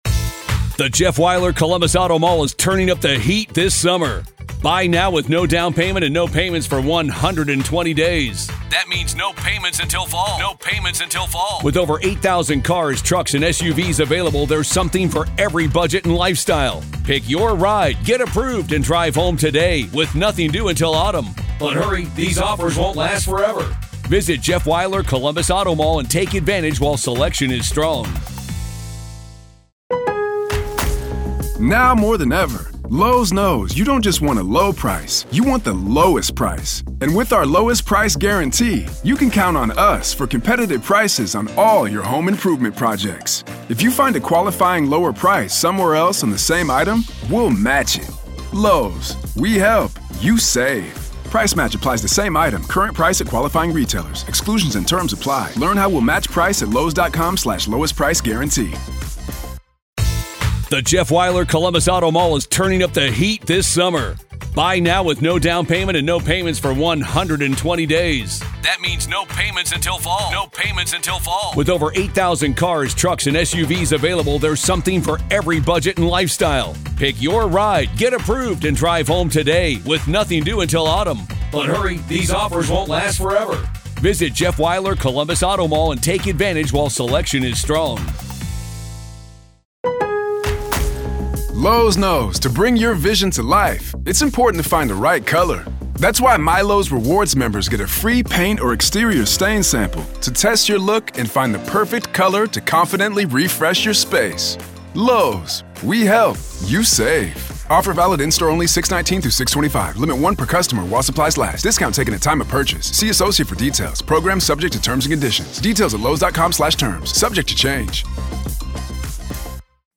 Exclusive: The Full Court Proceeding Where Lori Vallow and Chad Daybell's Cases are Severed